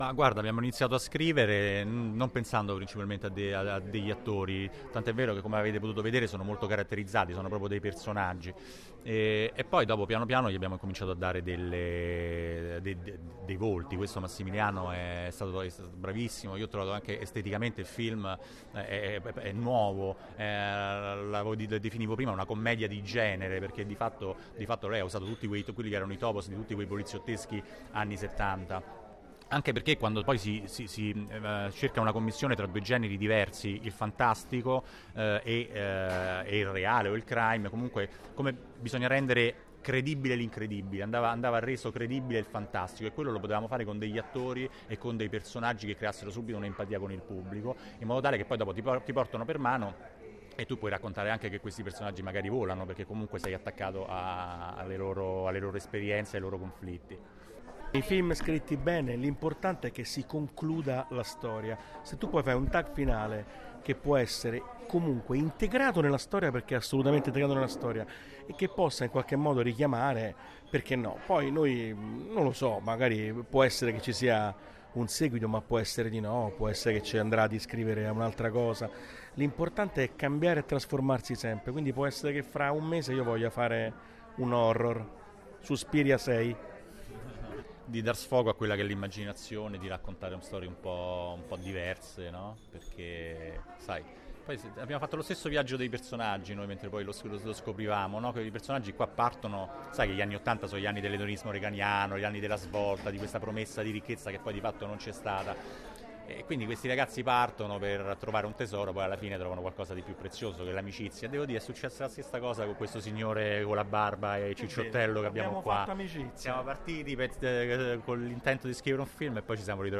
non-ci-resta-che-il-crimine-bruno-e-guaglianone-parlano-del-film.mp3